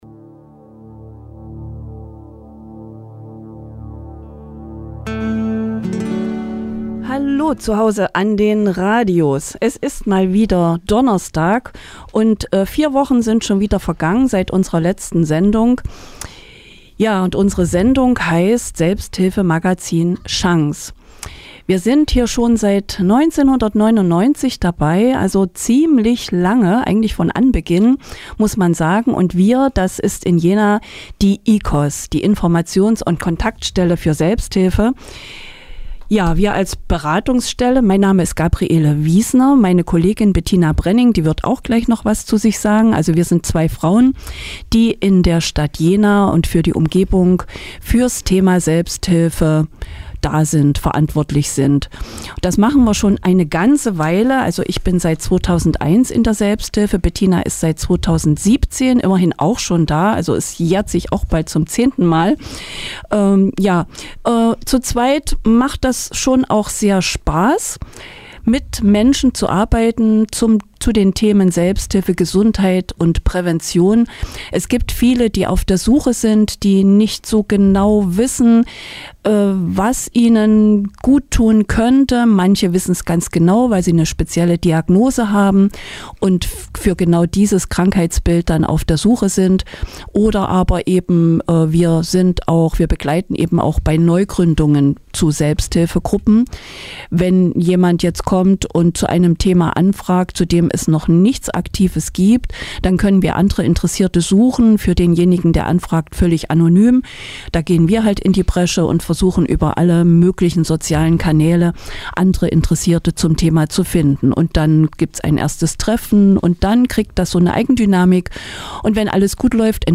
sind live auf Sendung